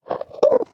Minecraft Version Minecraft Version 25w18a Latest Release | Latest Snapshot 25w18a / assets / minecraft / sounds / mob / endermen / idle5.ogg Compare With Compare With Latest Release | Latest Snapshot